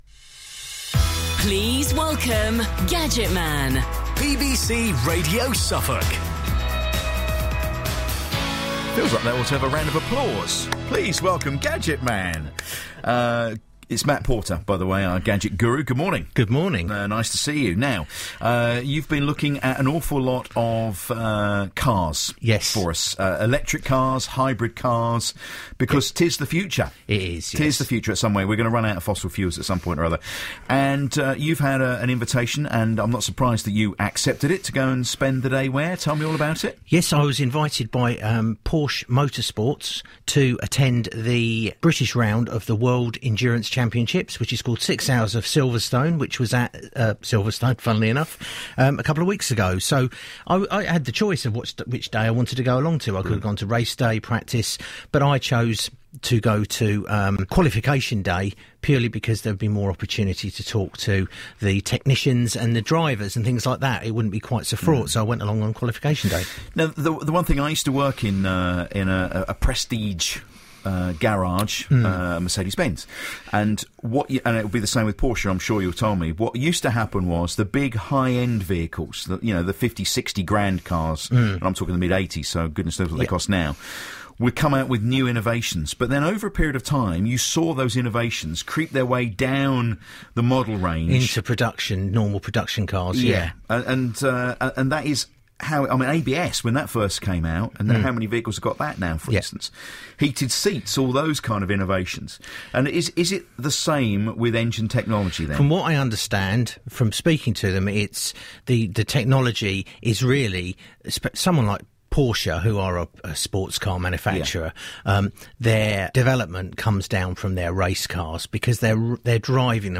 We had a wonderful day at the World Endurance Championship’s 6 Hours of Silverstone last weekend. Not only were we guests of the wonderful people at Porsche Motorsports, we were also able to chat to the Technicians and Drivers and find out more about how the idea of Hybrid power translates to the worlds fastest racecars.